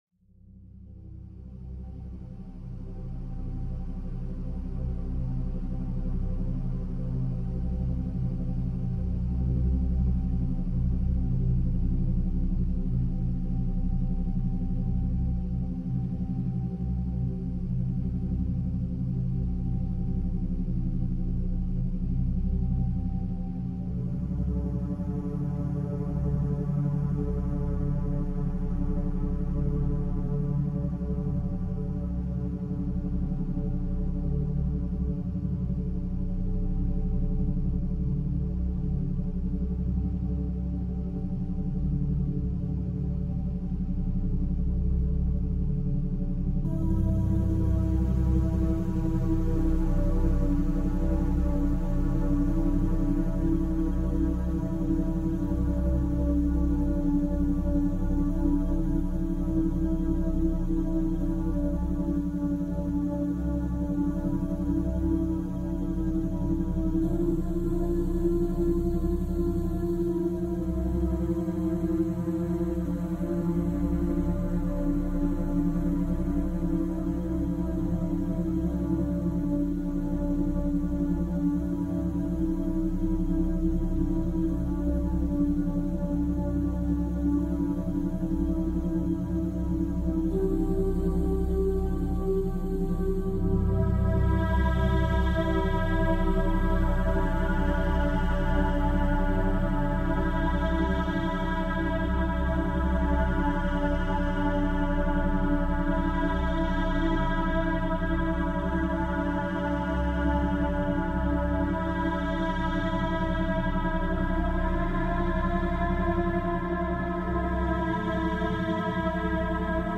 Es beginnt leise.